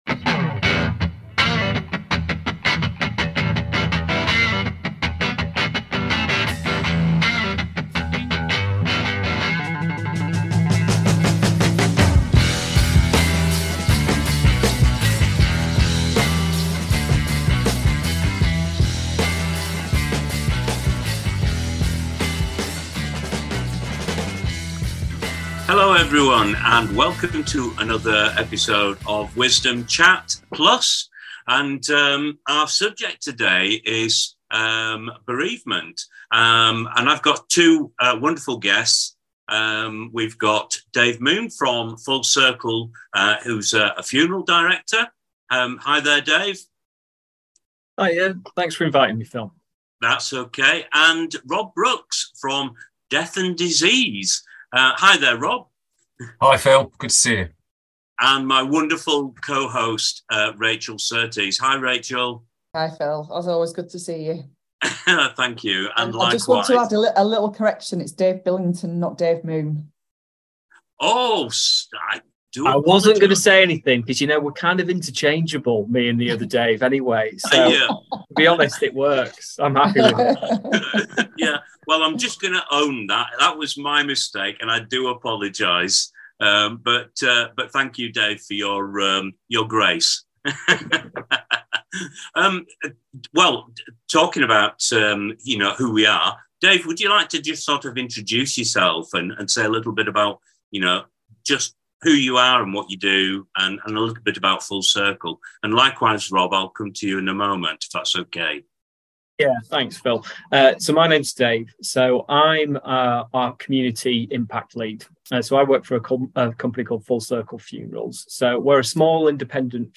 Death, Grief and Finances, A Candid conversation